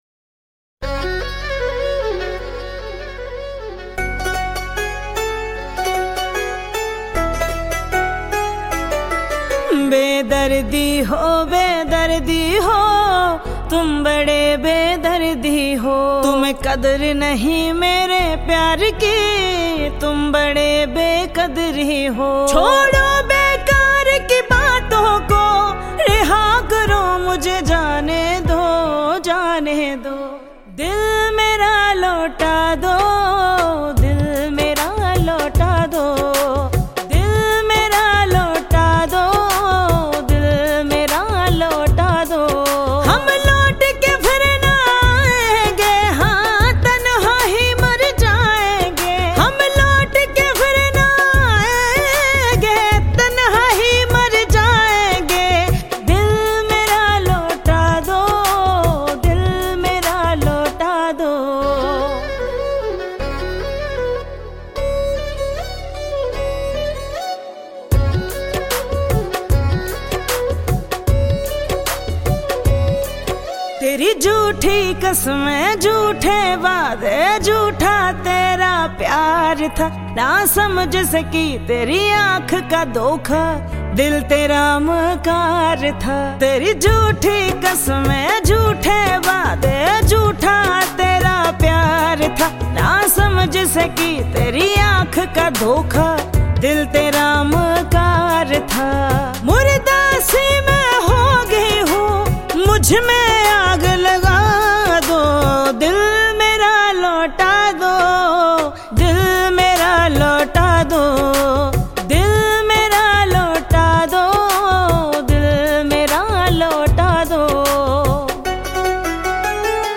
Hindi